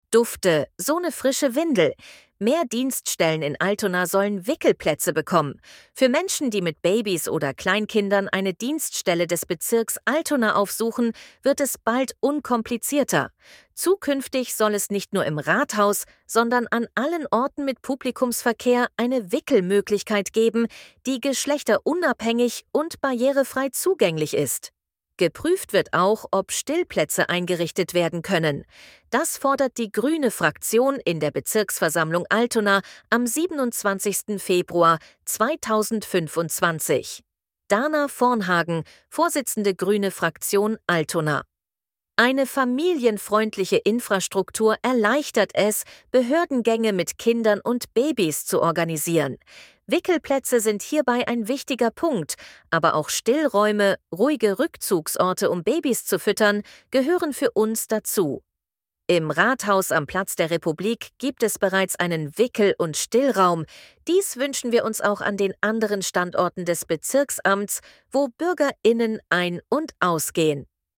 ElevenLabs2_Dufte-sone-frische-Windel.mp3